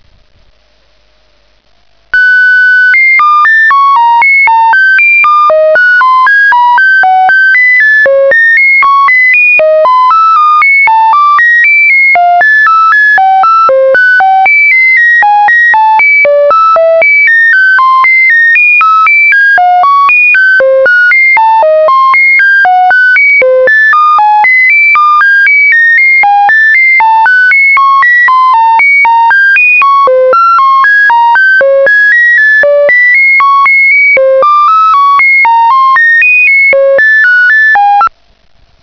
Espectro del modo Ros 4/2000.
Escuche como suena un CQ en este modo: